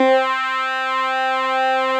Added synth instrument
snes_synth_048.wav